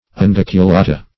Unguiculata \Un*guic`u*la"ta\, n. pl. [NL., fr. L. unguiculus a